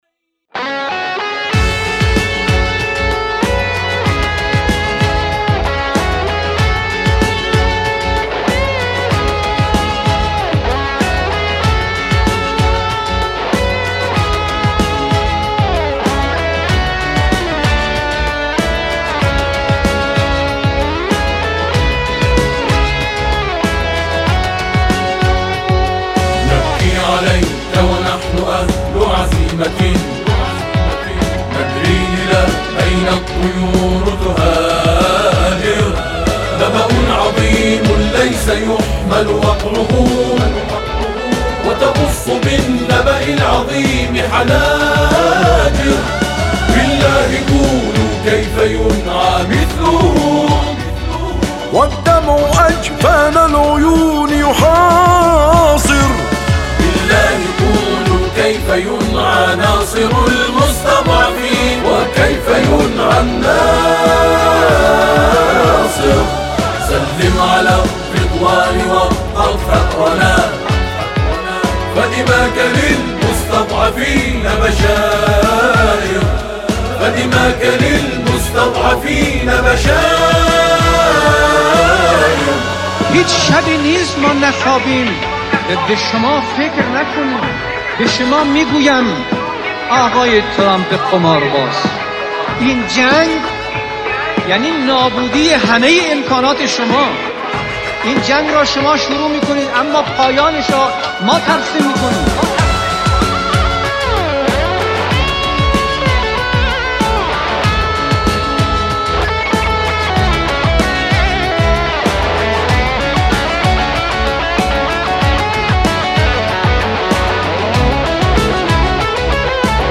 گروه تواشیح